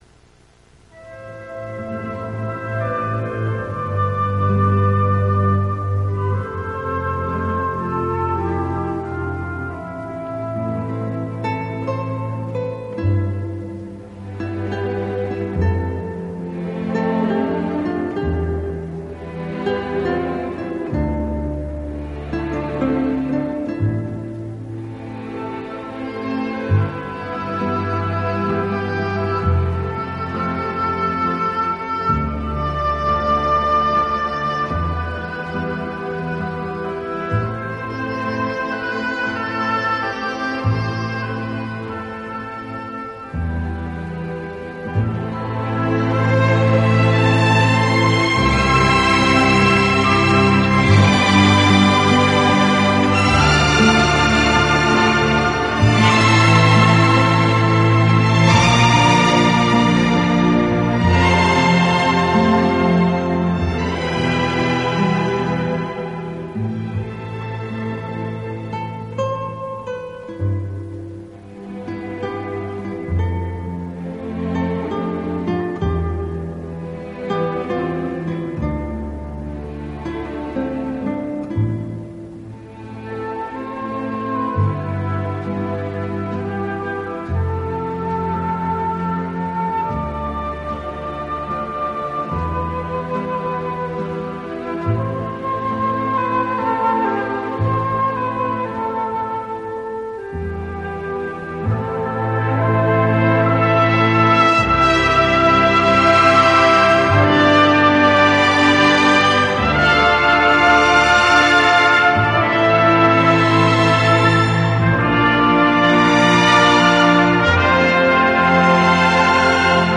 大量弦乐器织成的“弦瀑”
这个乐团的演奏风格流畅舒展，
旋律优美、动听，音响华丽丰满。